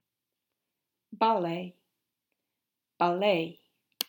最初のバレーパーキングと同じ、フランス語風で「バレイ」という感じです。
ballet.m4a